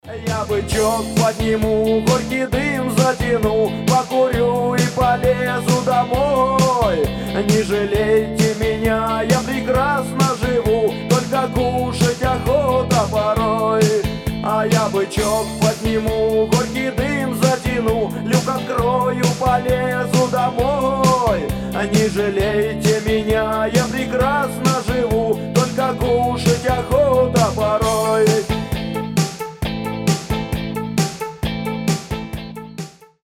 Рок рингтоны , Грустные
Панк-рок